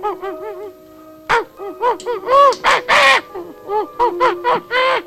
File:Baby sanda roar.ogg
Baby_sanda_roar.ogg